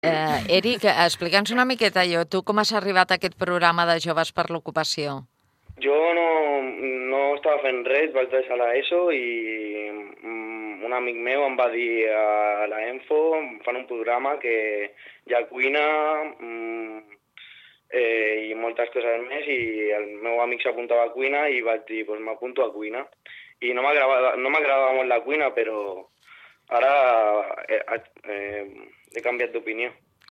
I aquest dilluns, a l’entrevista que van realitzar al “Posa’t les piles ” de Ràdio Mollet als joves participants del programa Joves per l’Ocupació es confirma que és així.